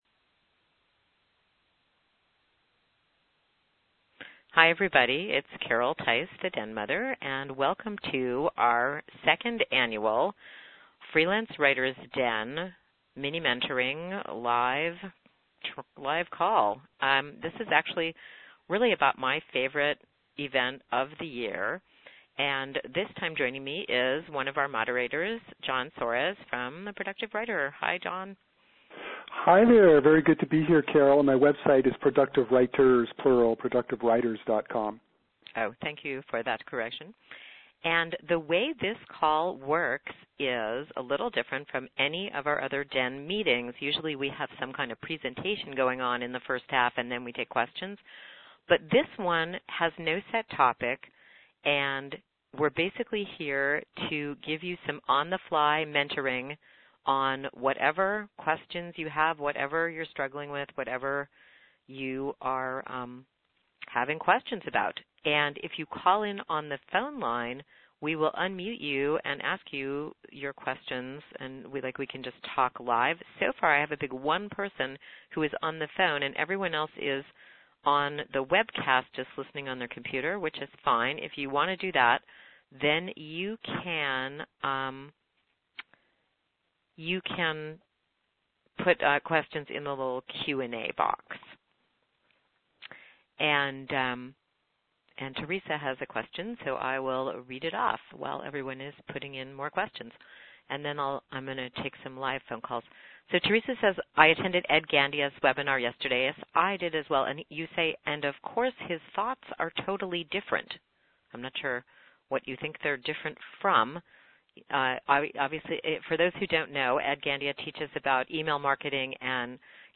My Interview with the Text and Academic Authors Association